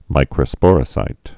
(mīkrə-spôrə-sīt)